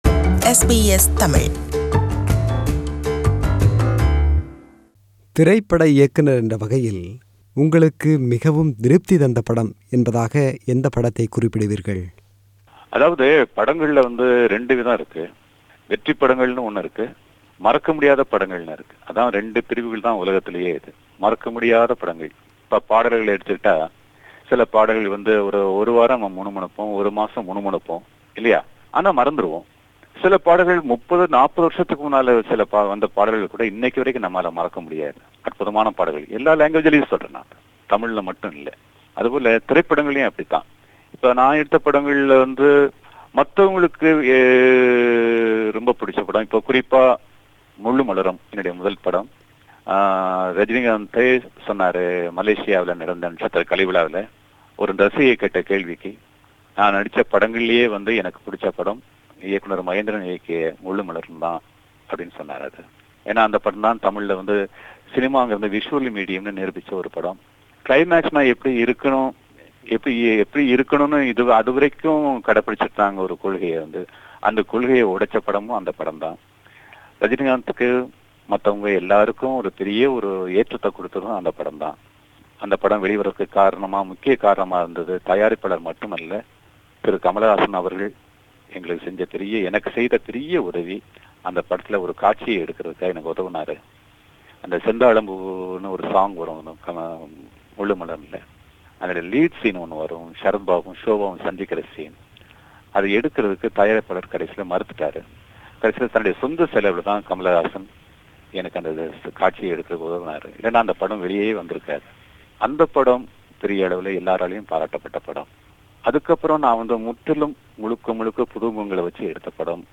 It's a rebroadcast of his interview with SBS Tamil.